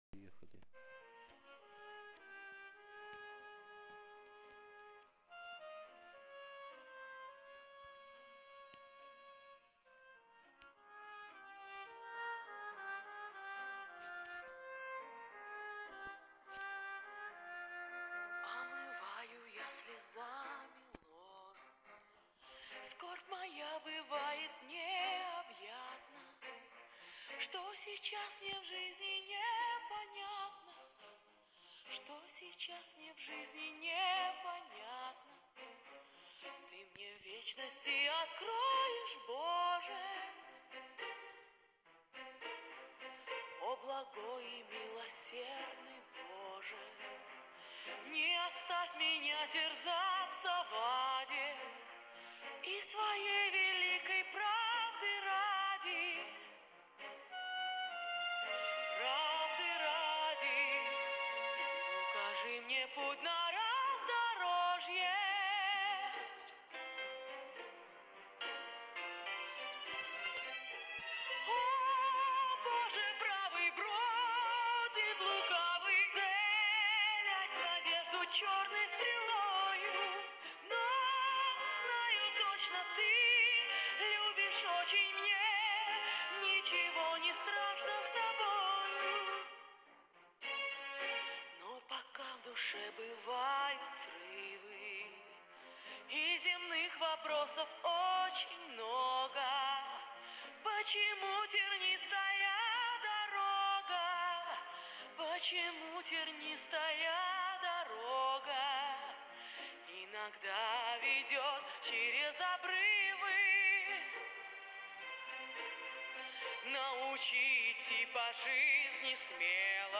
песня
157 просмотров 214 прослушиваний 7 скачиваний BPM: 106